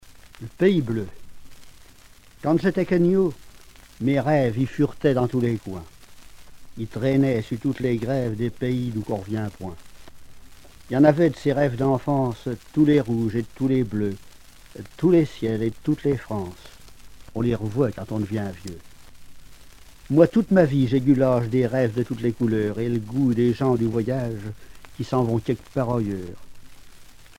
Langue Angevin
Genre poésie
Catégorie Récit